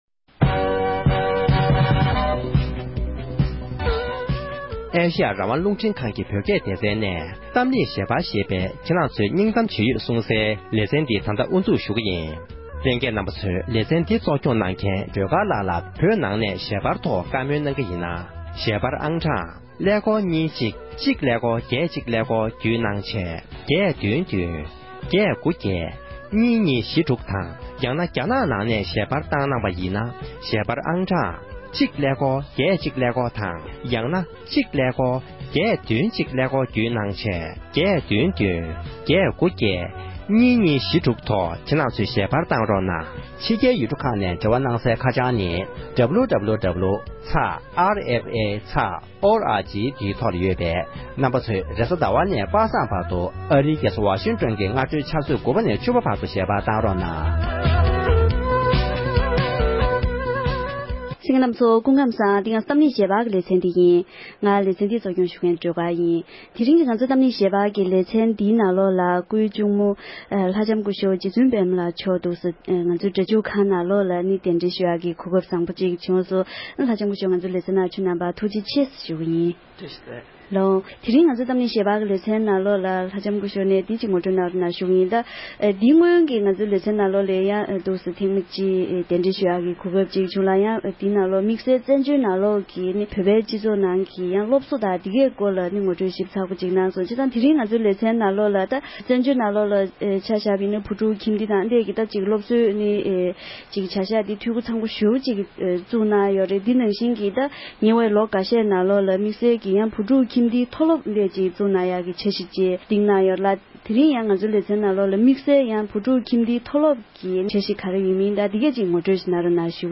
འདི་ག་རླུང་འཕྲིན་ཁང་གི་སྒྲ་འཇུག་ཁང་དུ་གདན་ཞུས་ཀྱིས་བོད་ཕྲུག་ཁྱིམ་སྡེ་གཙོས་བཙན་བྱོལ་ནང་གི་སློབ་གསོའི་གནས་སྟངས་སྐོར་གླེང་མོལ་ཞུས་པ།